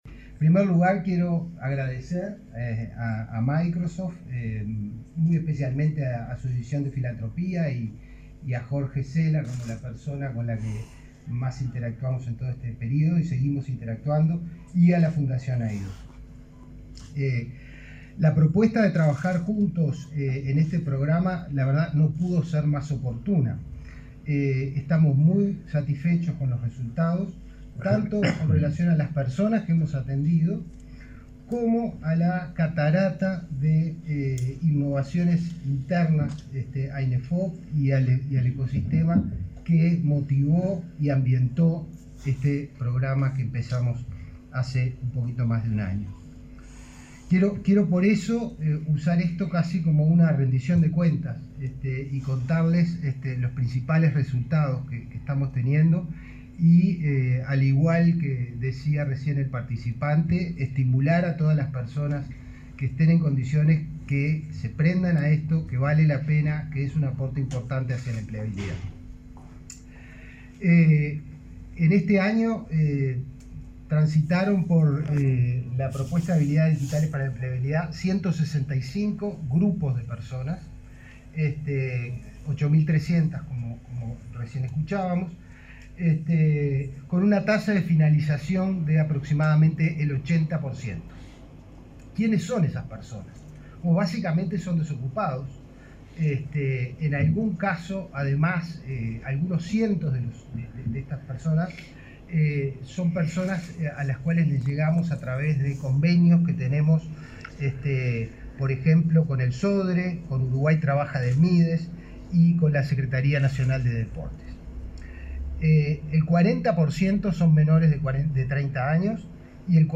Palabras del director general del Inefop, Pablo Darscht, y del ministro de Trabajo, Pablo Mieres
Este miércoles 18, el director general del Inefop, Pablo Darscht, y el ministro de Trabajo, Pablo Mieres, participaron del acto de firma de un nuevo